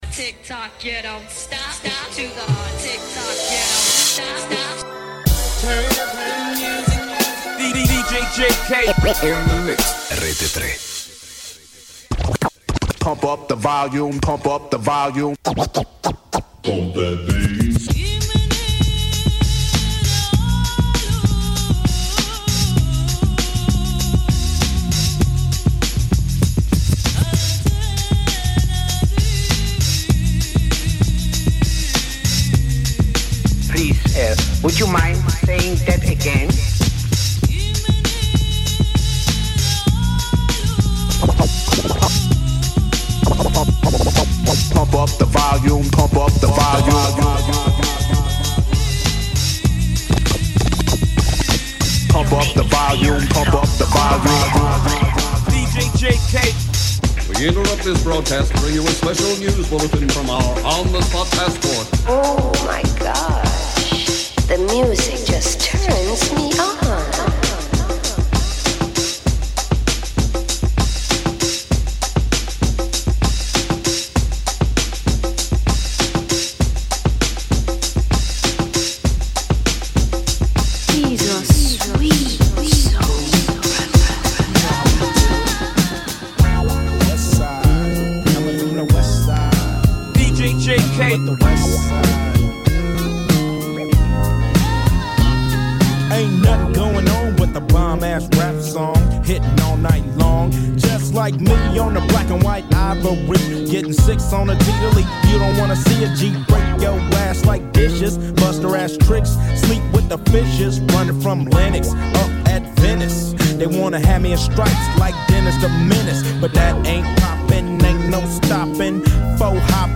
RAP / HIP-HOP